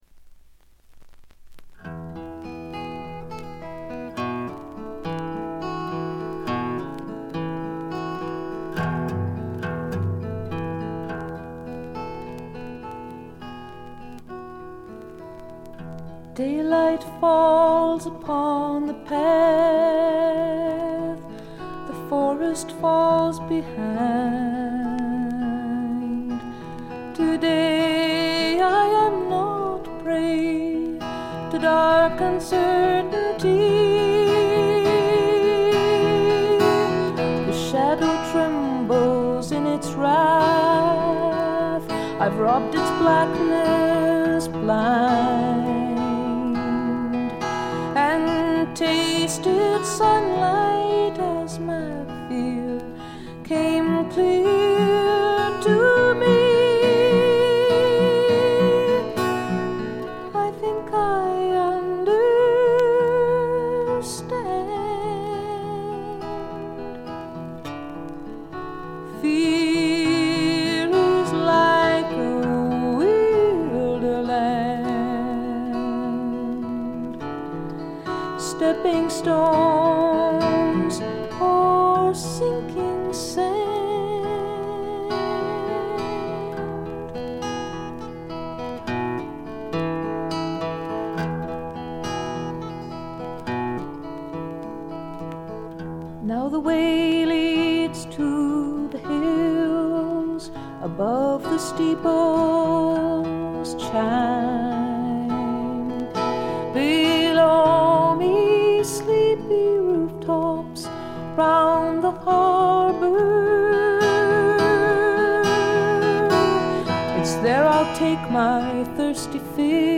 ところどころで軽微なバックグラウンドノイズに気づく程度。
透明感のあるみずみずしさが初期の最大の魅力です。
女性フォーク／シンガーソングライター・ファンなら避けては通れない基本盤でもあります。
試聴曲は現品からの取り込み音源です。
guitar, keyboards, vocals